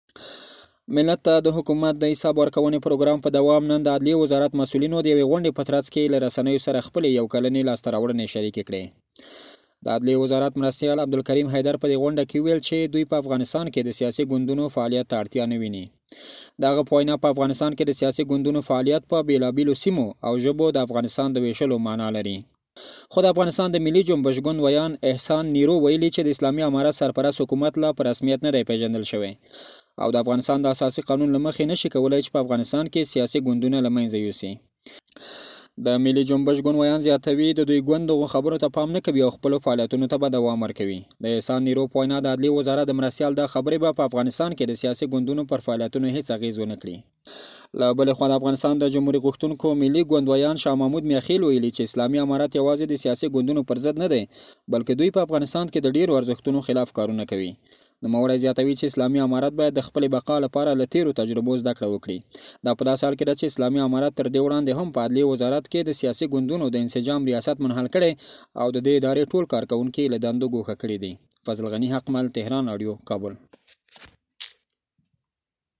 په دې باب له کابله زمونږ د خبریال رالیږلی راپور په ګډه سره آورو.